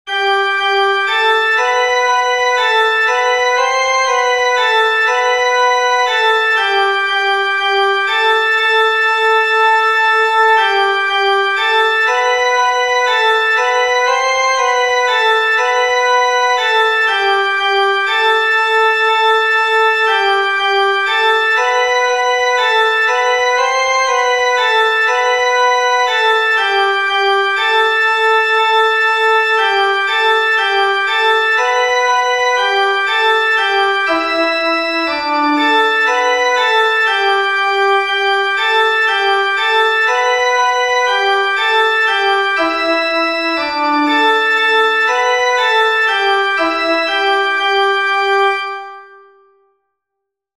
Tune: UBI CARITAS (Plainsong)
Piano/Organ